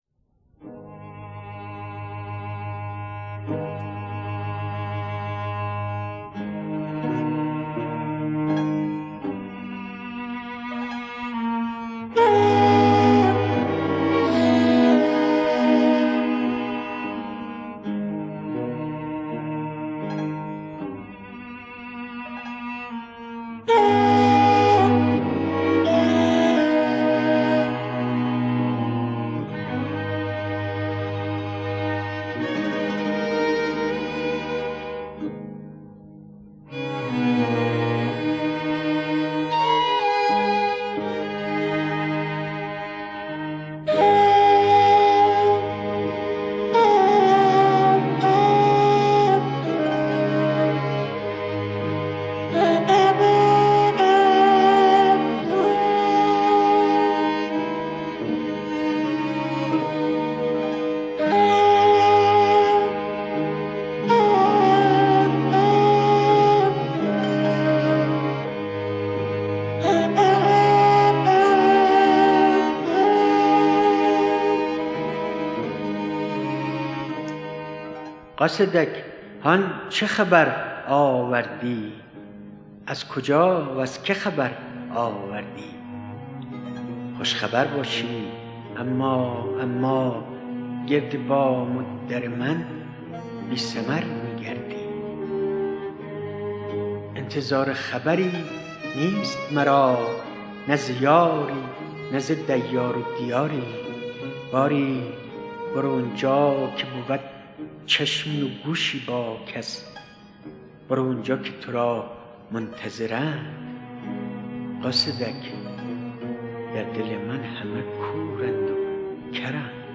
دانلود نسخه صوتی دکلمه دانلود / گوینده: [مهدي اخـوان ثالث]
اطلاعات دکلمه
گوینده :   [مهدي اخـوان ثالث]